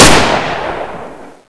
Gunshot.wav